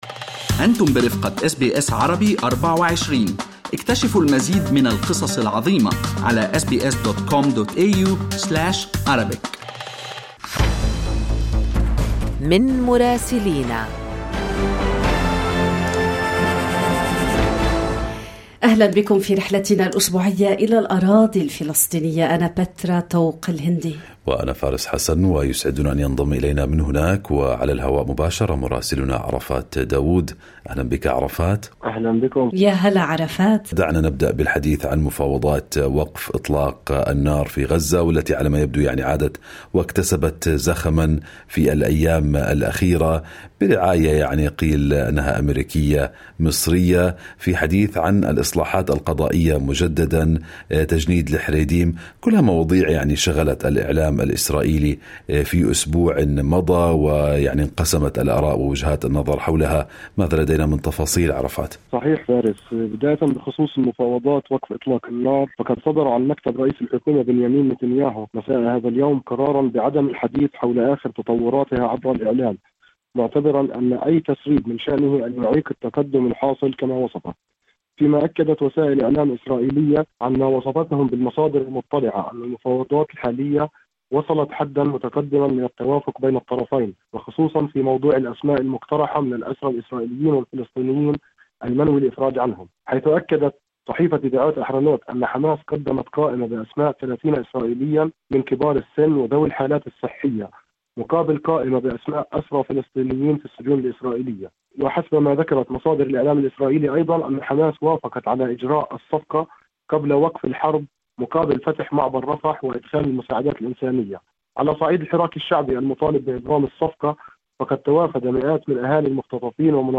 المزيد في تقرير مراسلنا من الأراضي الفلسطينية، تجدونه كاملاً عبر الضغط على الرابط الصوتي أعلى الصفحة.